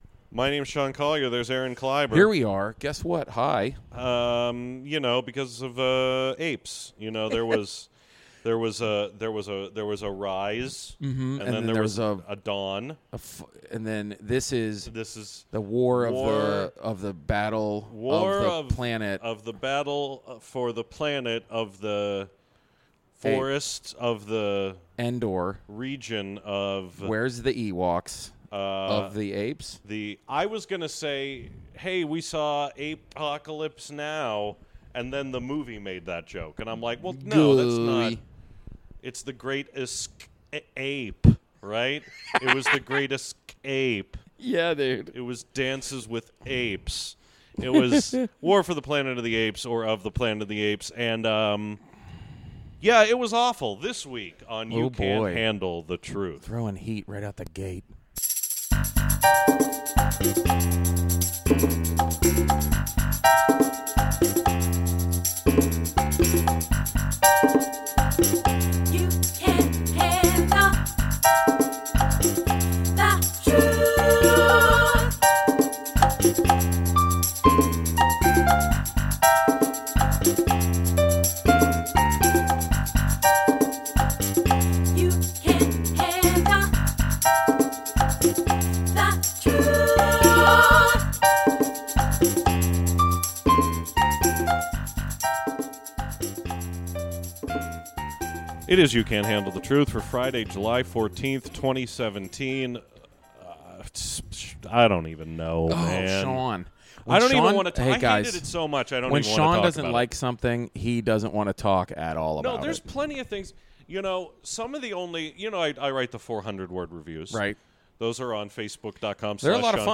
Every week we bring you reviews of movies, recorded immediately after an advance screening.